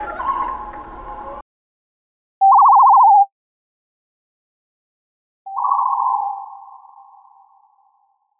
loon calls, in three parts. part 1: real canadian loon call part 2: synthesized call (no reverb) part 3: synthesized call (with reverb)